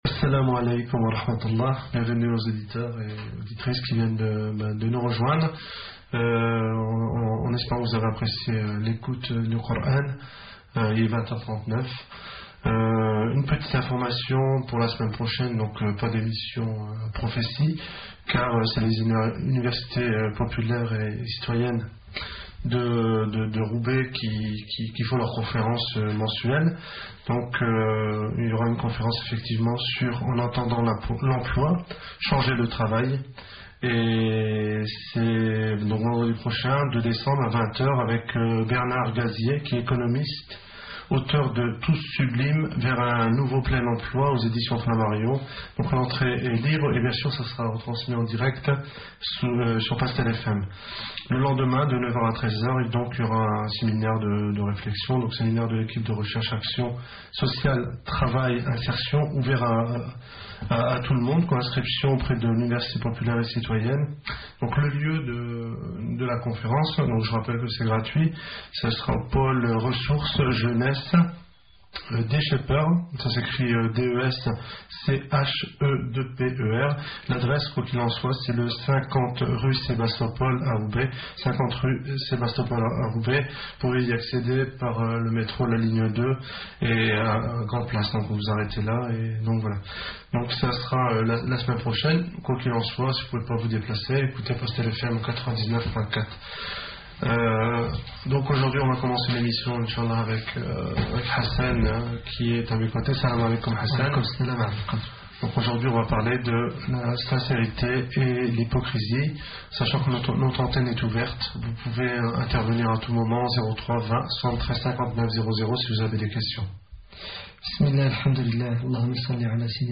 PASTEL FM l'émission la prophétie du vendredi 25 novembre 2005 pastelfm Conférence de : hassan IQUIOUSSEN Titre : La sincérité, l'hypocrisie